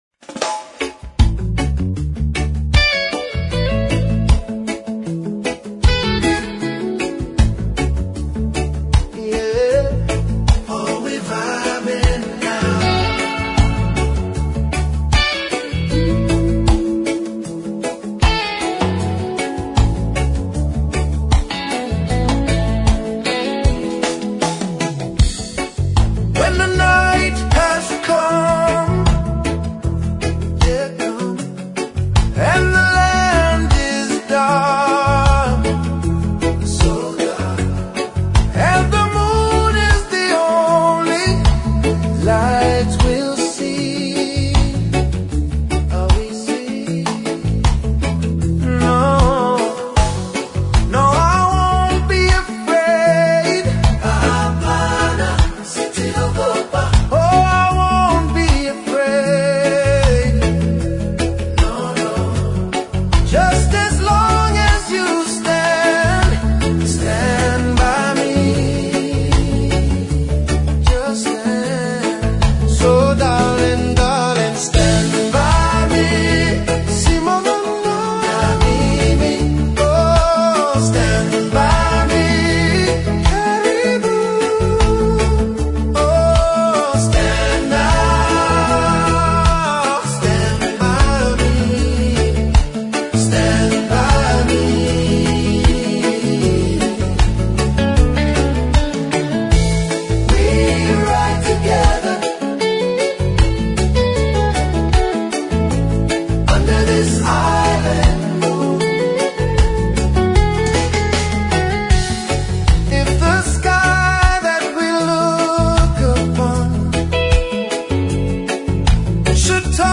Reggae Cover